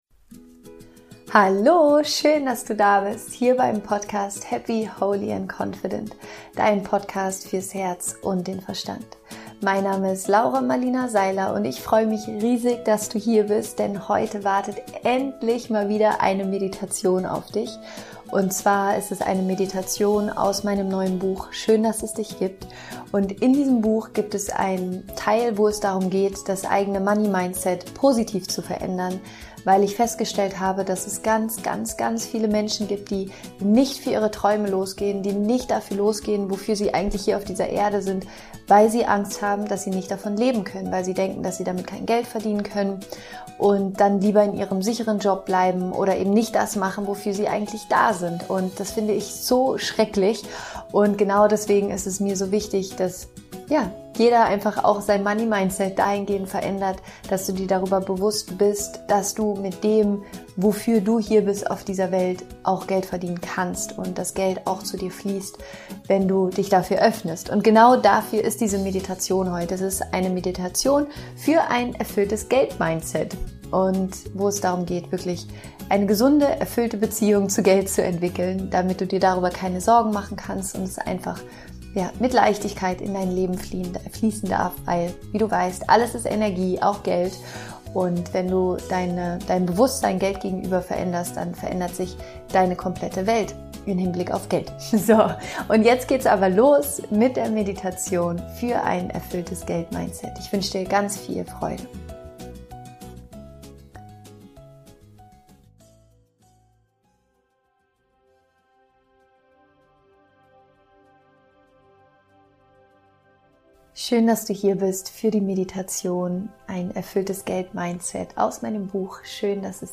Meditation für ein erfülltes Money-Mindset